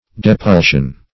Search Result for " depulsion" : The Collaborative International Dictionary of English v.0.48: Depulsion \De*pul"sion\, n. [L. depulsio.] A driving or thrusting away.